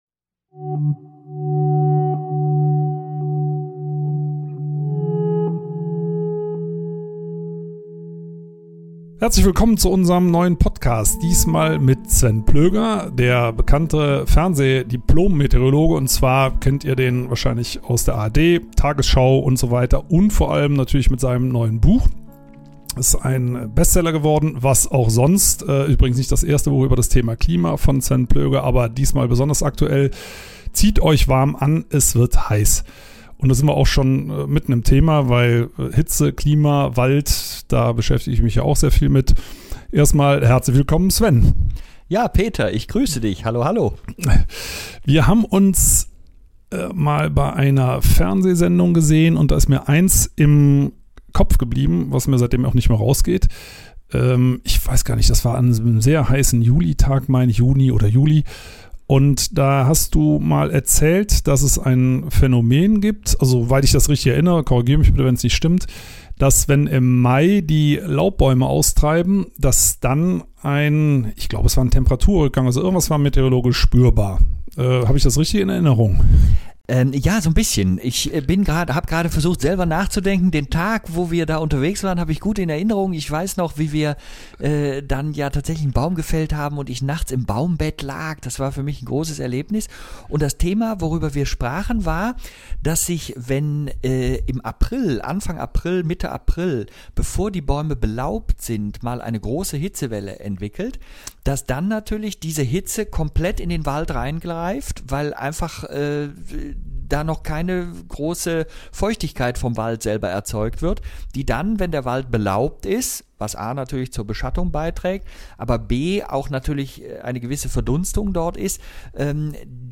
Peter Wohlleben und Diplom-Meteorologe Sven Plöger sprechen über die Rolle des Waldes im Klimawandel, Lehren die wir aus der der Corona-Krise ziehen können und ob Freiwilligkeit zum Erfolg führen kann.